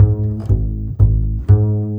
Rock-Pop 11 Bass 10.wav